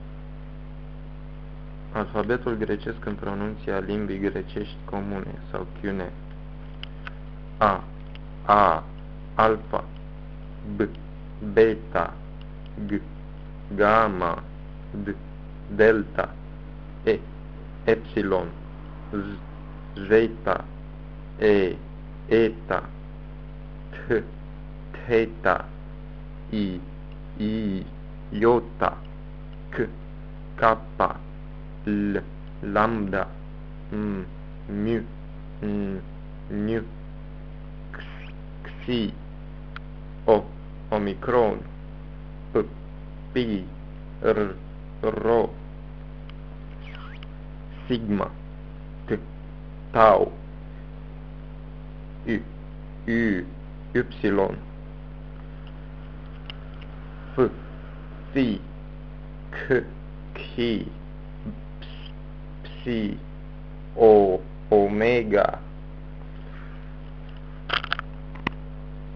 Pronunția alfabetului
Alfabet_koine.ogg.mp3